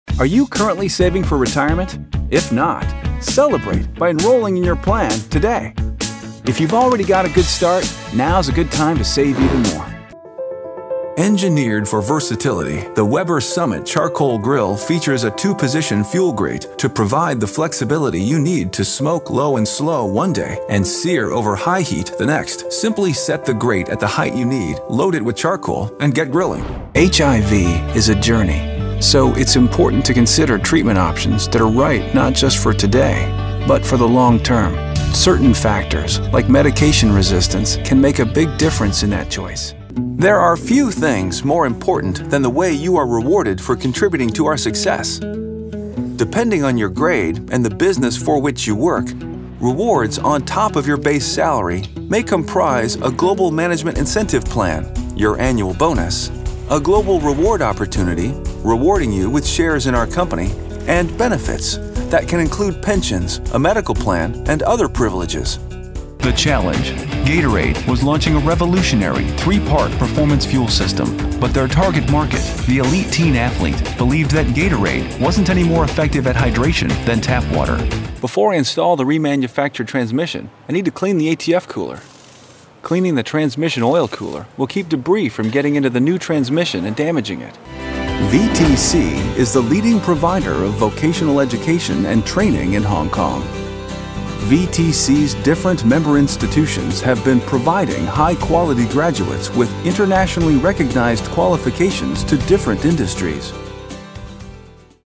Guy Next Door, Trustworthy, Honest, Intelligent, Strong, Believable, Real, Energetic, Conversational, Tech Savvy
Sprechprobe: Industrie (Muttersprache):